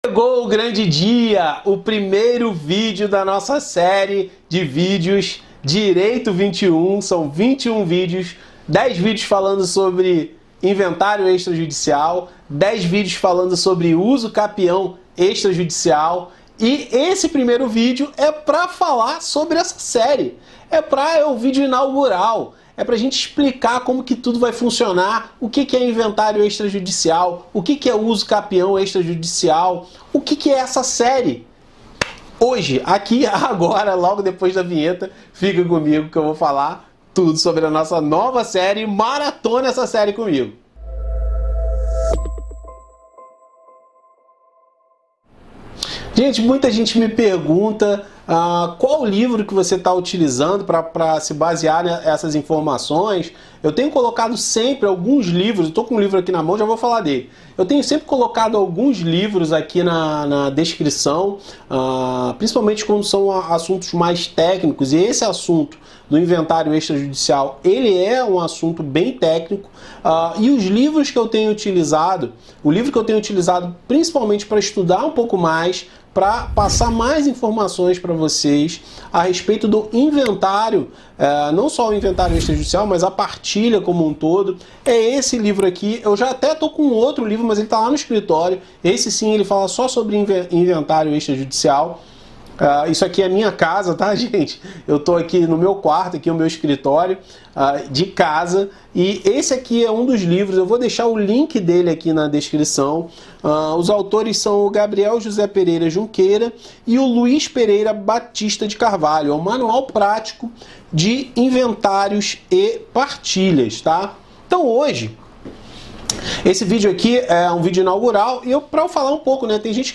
Inventário Extrajudicial e Usucapião Extrajudicial (Direito XXI 21 vídeos 01 Aula Inaugural).